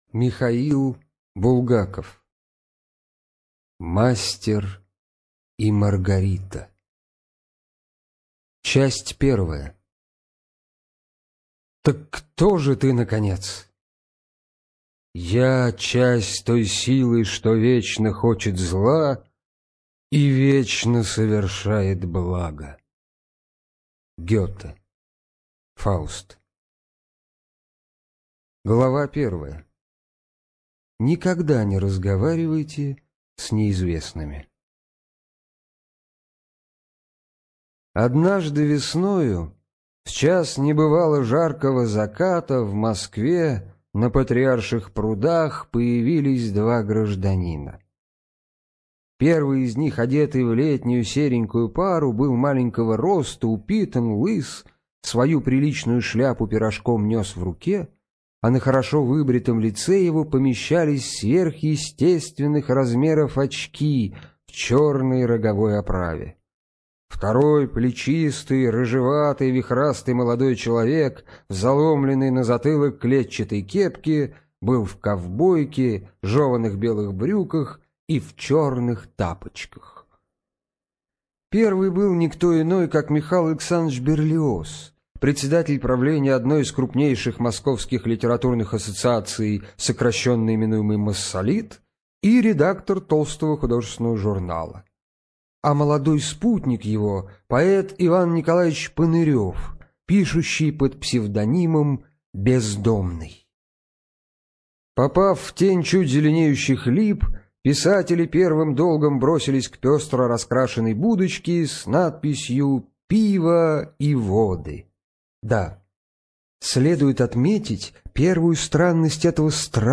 ЖанрКлассическая проза, Советская проза